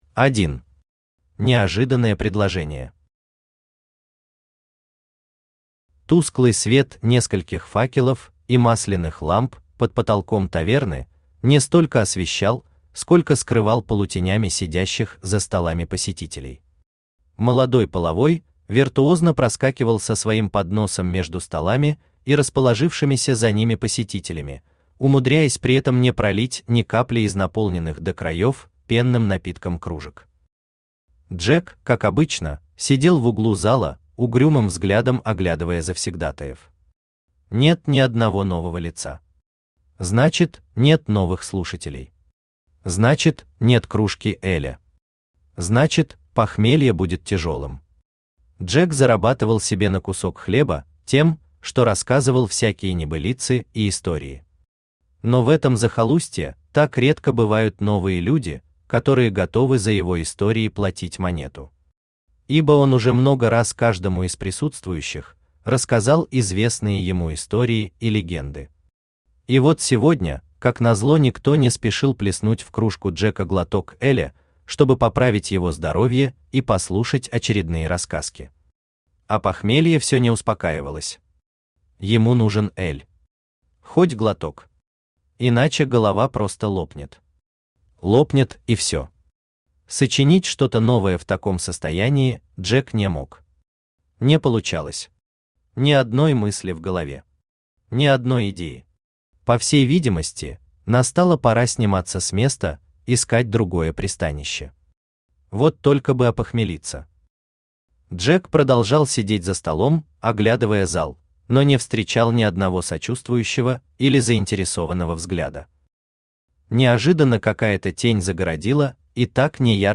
Аудиокнига Курьер королевы | Библиотека аудиокниг
Aудиокнига Курьер королевы Автор Равиль Тимиргалиевич Таминдаров Читает аудиокнигу Авточтец ЛитРес.